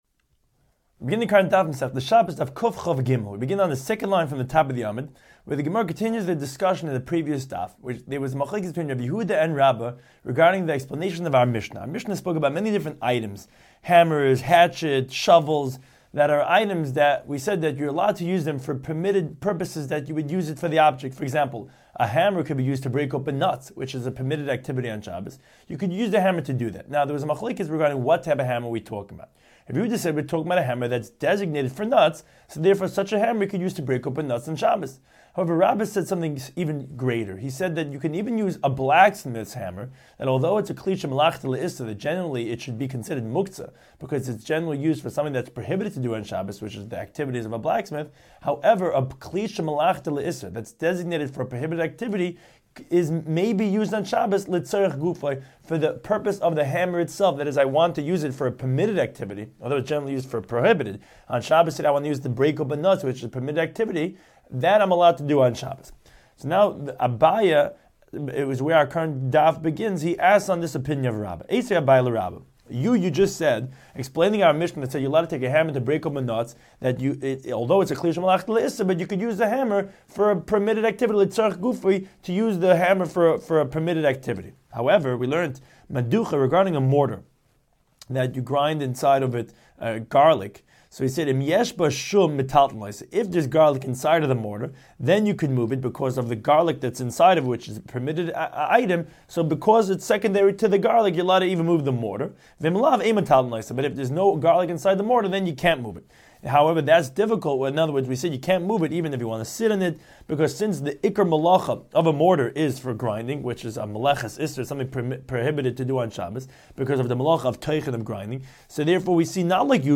Daf Hachaim Shiur for Shabbos 123